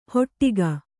♪ hoṭṭiga